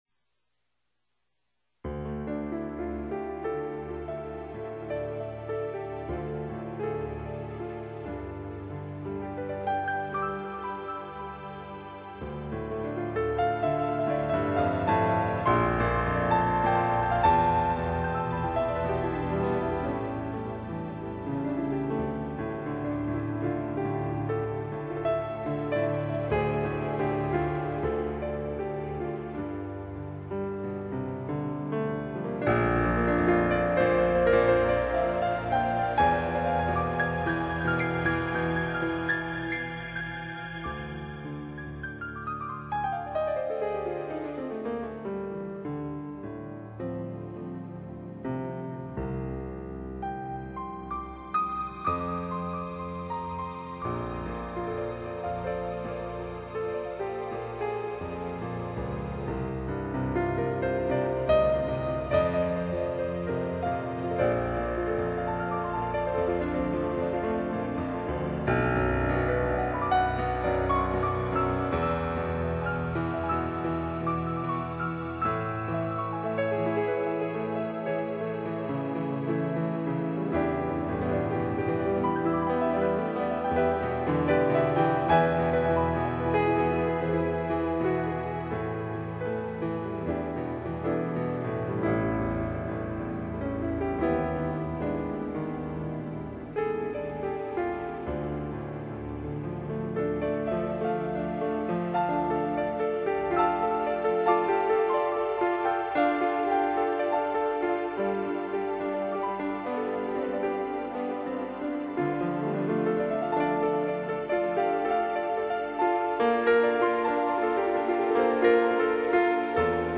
American Pianist
AUDIO RECORDINGS (Piano)
Romantic Candlelight Jazz Piano Music